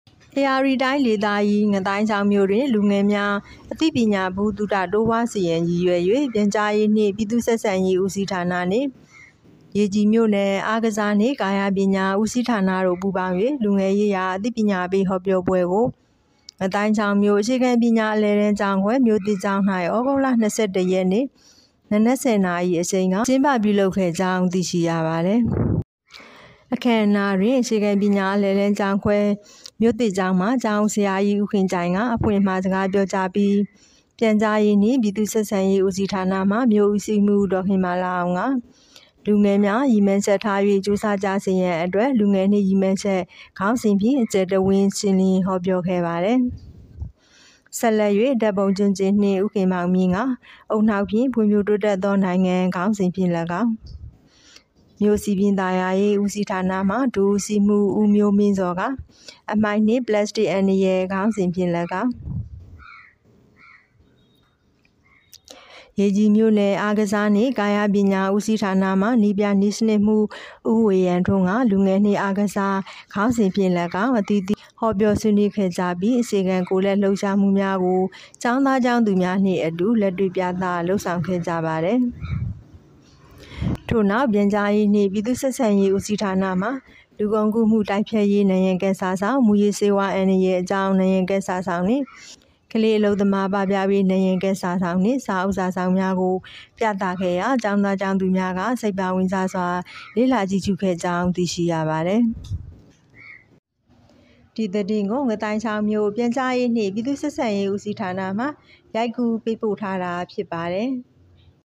ငါးသိုင်းချောင်းမြို့တွင်လူငယ်ရေးရာအသိပညာပေးဟောပြောပွဲကျင်းပ